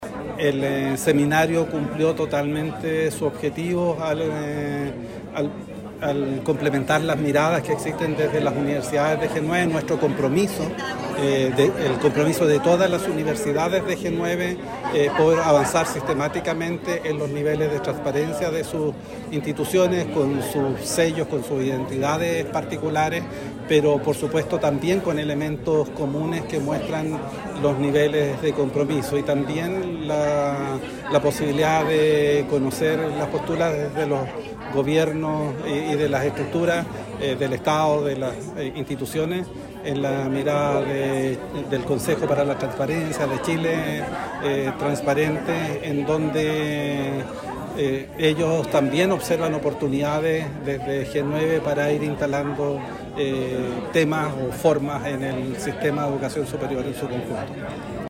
Con una fuerte apuesta por el fortalecimiento de la confianza pública y el acceso abierto a la información, las universidades agrupadas en la Red G9 celebraron el pasado viernes 28 de marzo el seminario “Transparencia en Educación Superior: avances y desafíos” en la Unidad de Santiago de la Universidad de Concepción (UdeC).